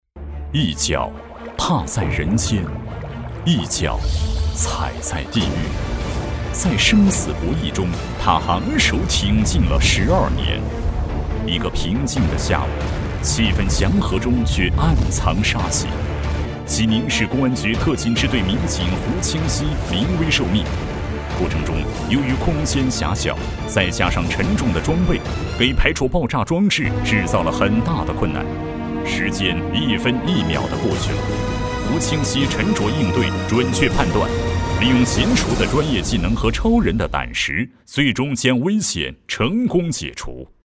稳重磁性 人物专题
大气浑厚，稳重磁性男音。擅长记录片，专题汇报，企业宣传片等不同题材。